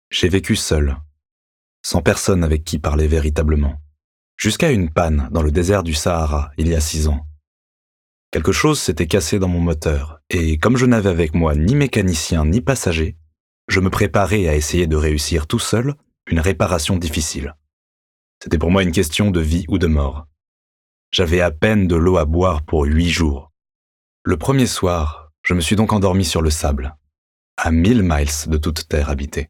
15 - 60 ans - Baryton